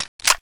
menuback.wav